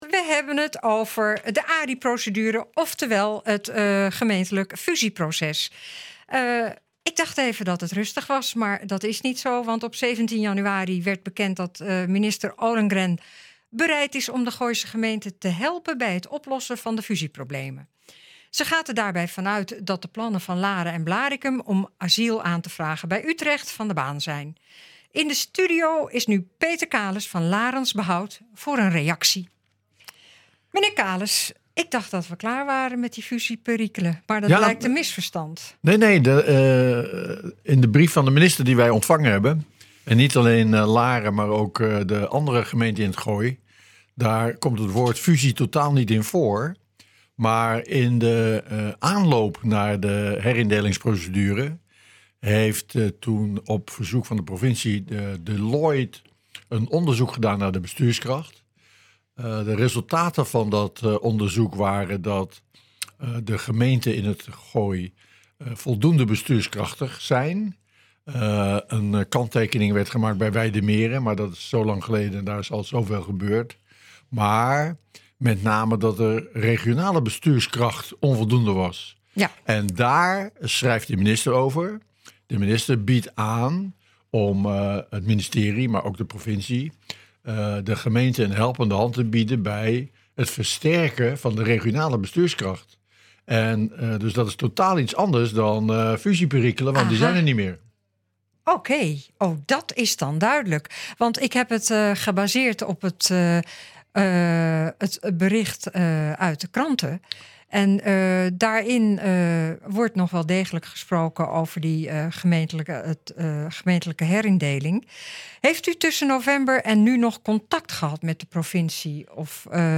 Op 17 januari werd bekend dat Minister Ollengren bereid is de Gooise gemeenten te helpen bij het oplossen van de fusieproblemen. Zij gaat er hierbij vanuit dat de plannen van Laren en Blaricum om asiel aan te vragen bij Utrecht, van de baan zijn. In de studio